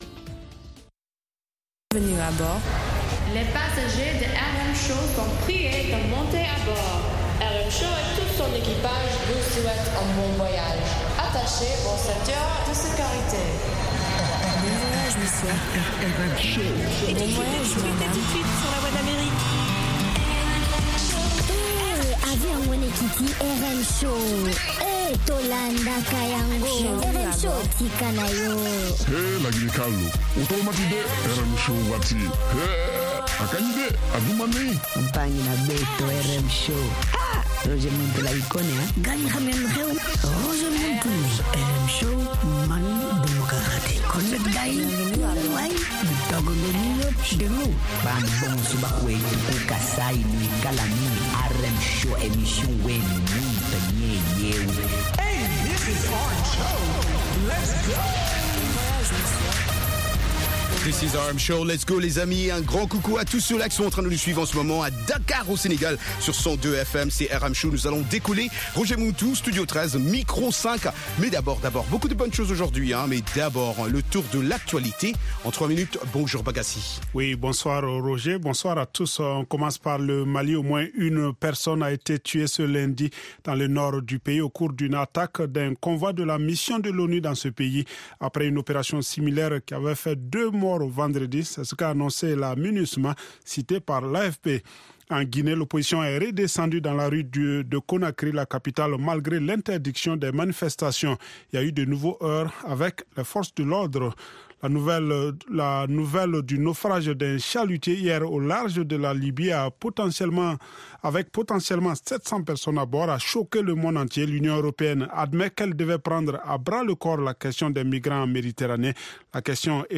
Palmarès des chansons à la mode, en rapport avec les meilleures ventes de disques aux Etats-Unies